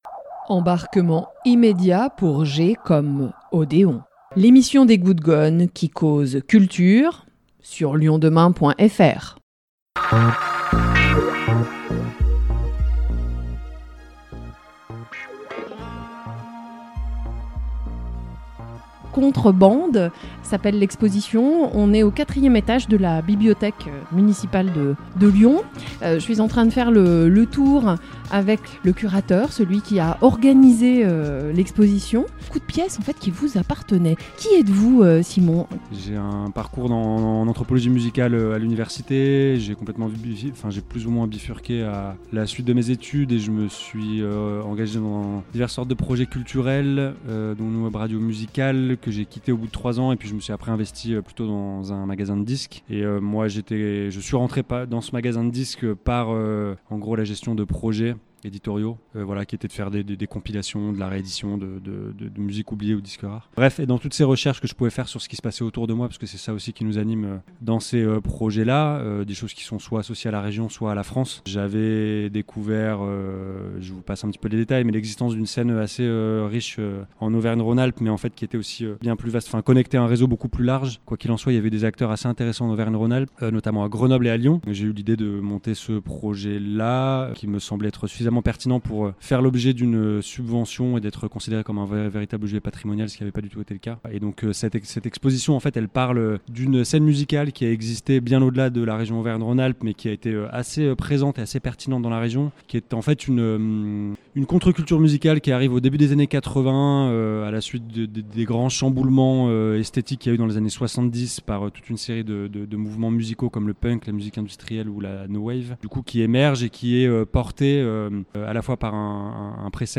Cette semaine, je vous propose une visite de l'exposition CONTRE BANDE, Musiques alternatives et culture cassette en Auvergne Rhône-Alpes 1980-1999